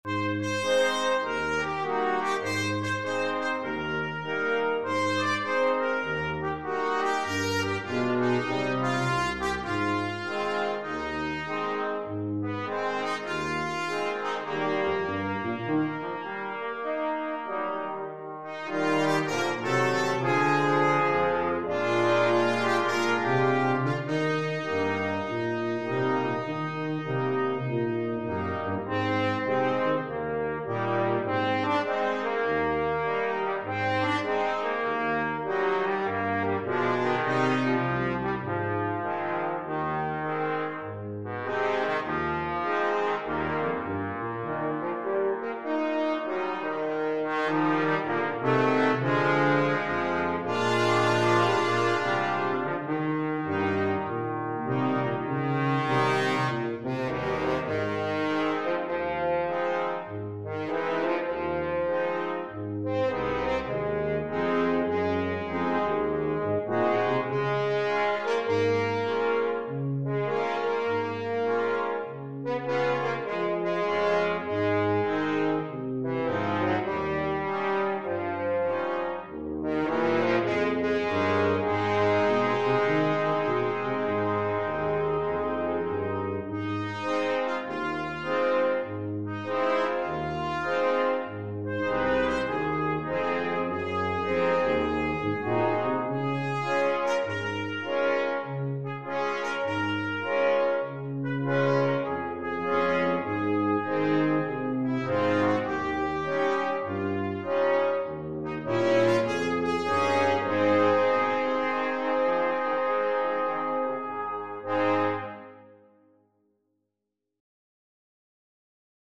Trumpet 1Trumpet 2French HornTromboneTuba
4/4 (View more 4/4 Music)
Swung
Brass Quintet  (View more Easy Brass Quintet Music)
Jazz (View more Jazz Brass Quintet Music)